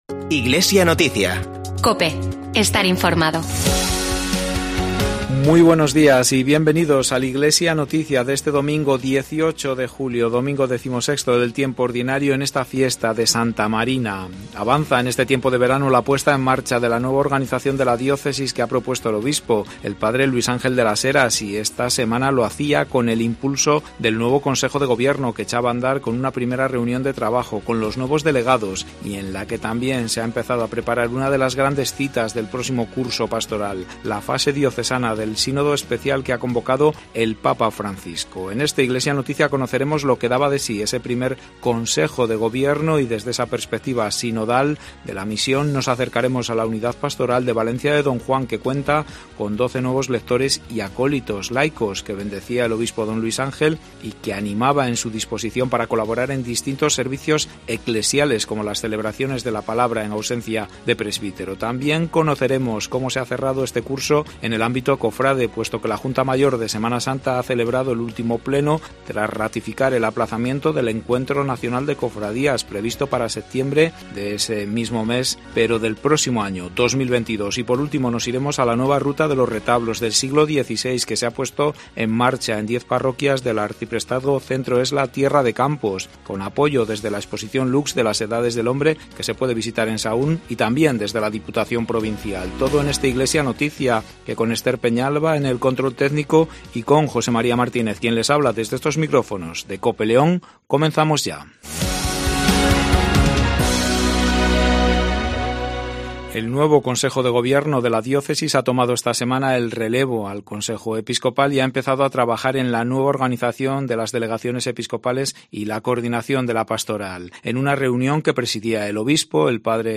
INFORMATIVO DIOCESANO